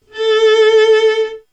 Toner du kan arbejde med: violin1   violin2
violin3.wav